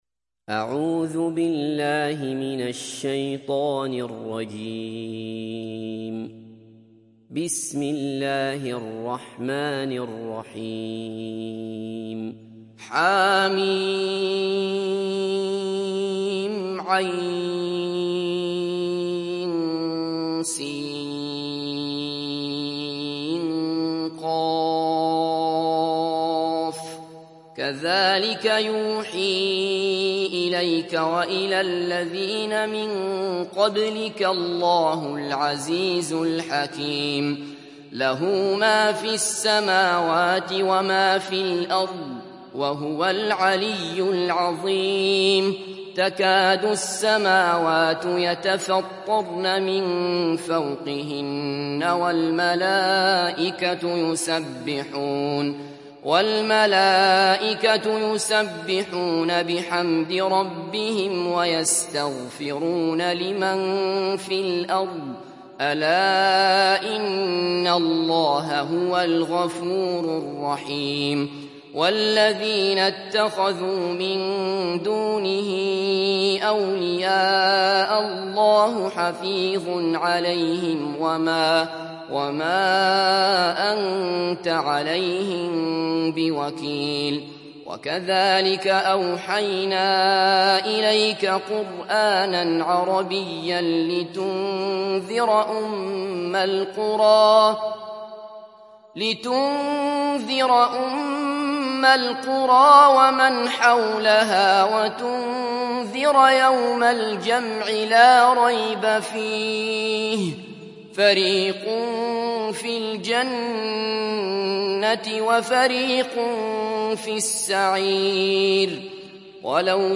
Surat Ash Shura Download mp3 Abdullah Basfar Riwayat Hafs dari Asim, Download Quran dan mendengarkan mp3 tautan langsung penuh